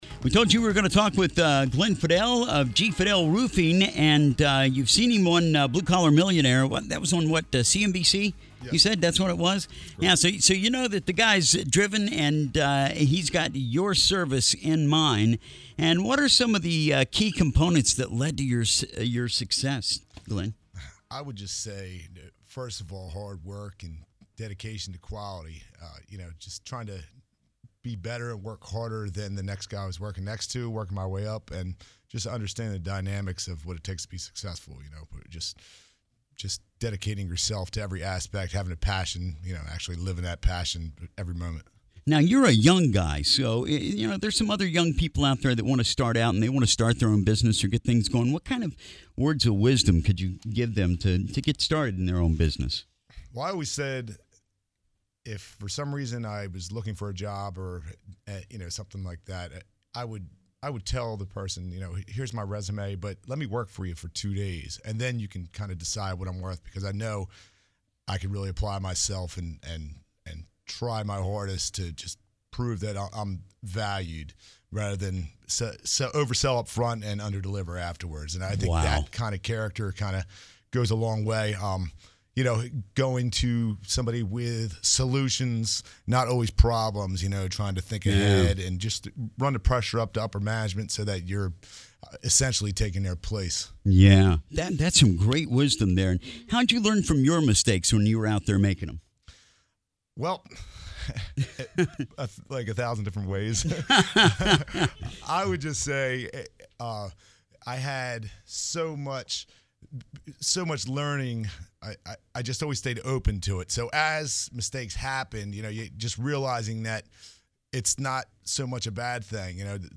94.7 Radio Interview